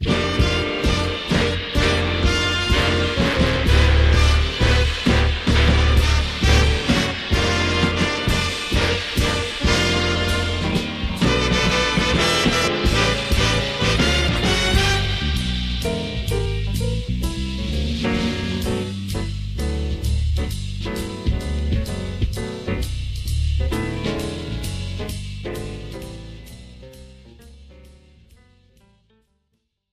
This is an instrumental backing track cover
• Key – D
• Without Backing Vocals
• No Fade